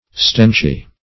stenchy - definition of stenchy - synonyms, pronunciation, spelling from Free Dictionary Search Result for " stenchy" : The Collaborative International Dictionary of English v.0.48: Stenchy \Stench"y\, a. Having a stench.